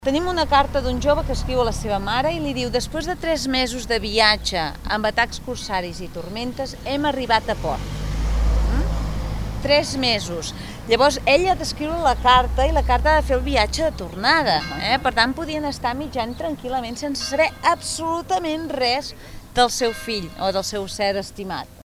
Ràdio Capital ha seguit una visita guiada que organitza Begur pels espais més interessant de l’arquitectura i la cultura indiana al municipi.